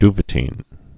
(dvə-tēn, dy-, dvə-tēn, dy-)